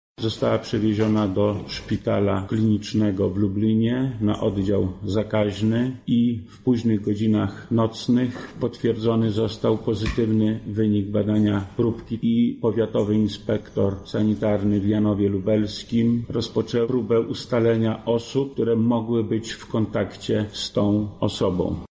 Za nami druga konferencja prasowa Wojewody Lubelskiego w sprawie koronawirusa
-mówi Wojewoda Lubelski Lech Sprawka.